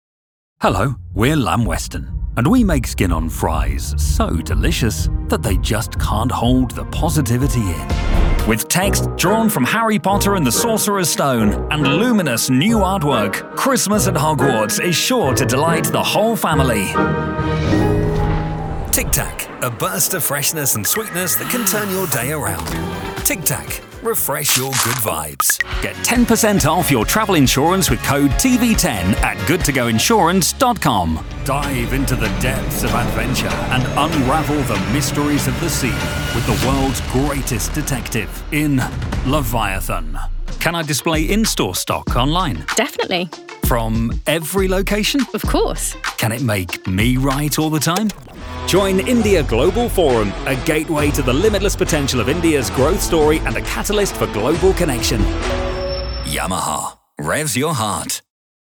A warm, friendly and conversational British voice. Neutral accent and easily understood all over the world!
VOICE ACTOR DEMOS